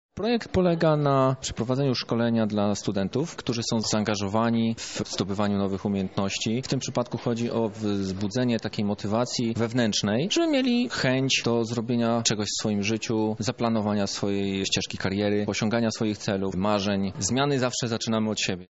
O szczegółach szkolenia mówi